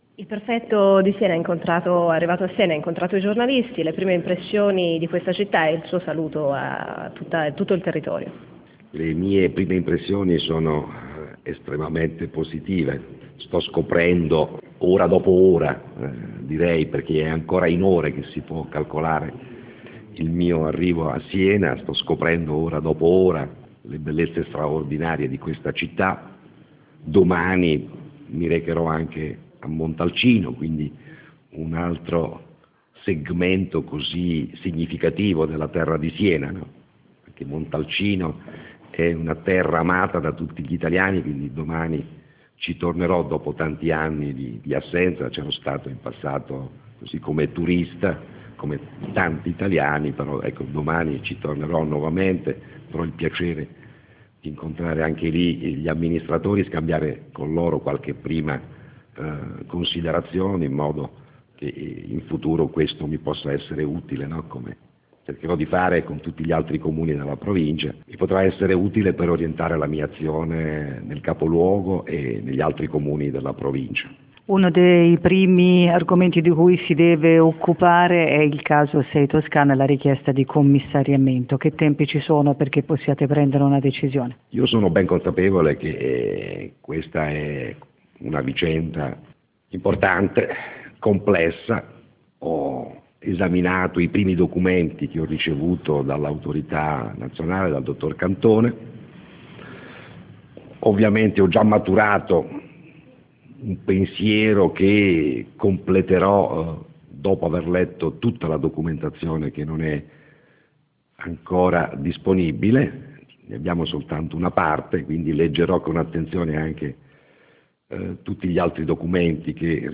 Si è presentato ufficialmente questa mattina alla stampa il nuovo Prefetto di Siena Armando Gradone, che è arrivato in città da pochi giorni.